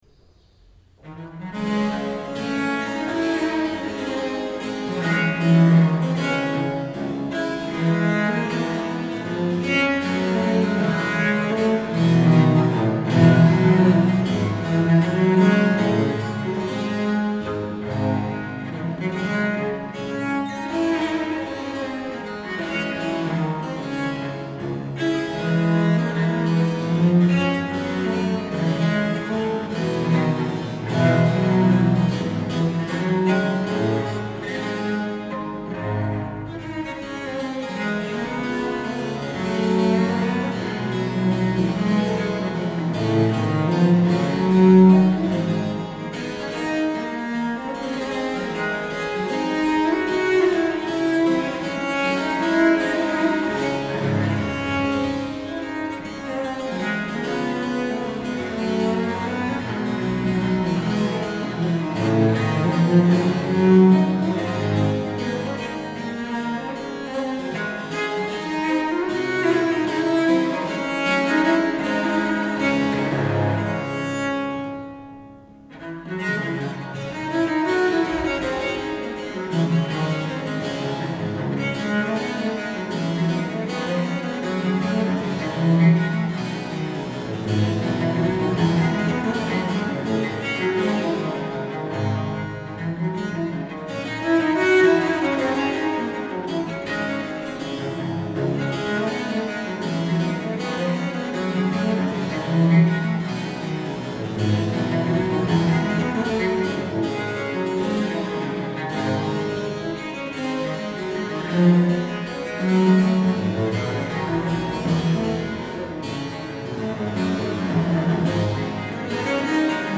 viola da gamba
clavicembalo